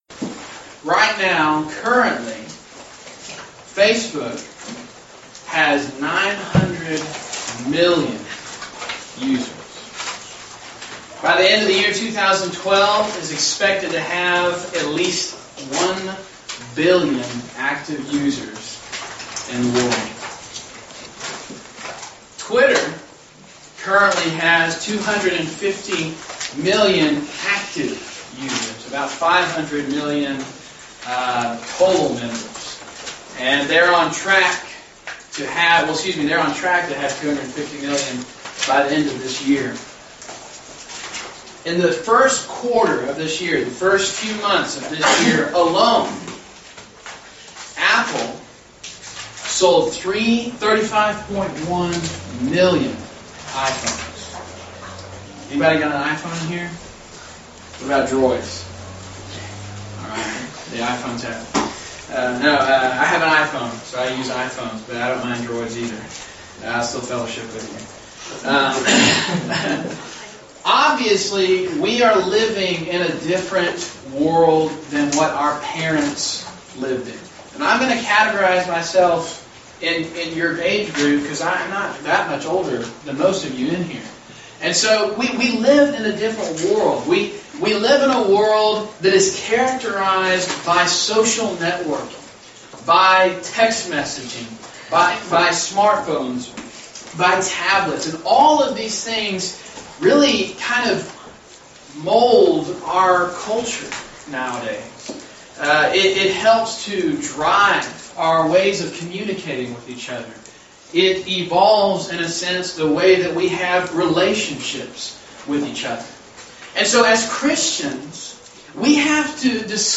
Event: Discipleship University 2012
Youth Sessions